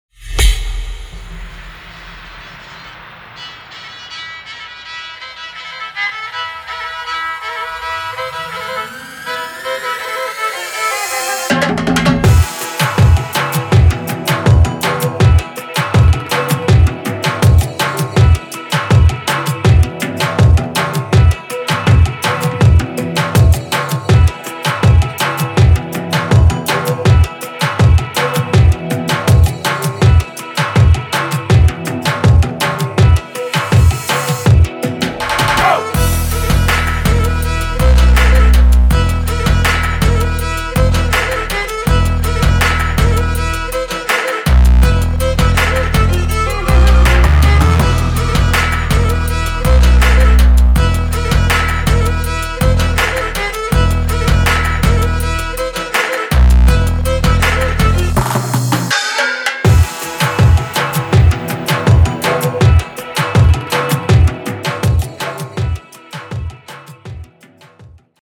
Live Sounds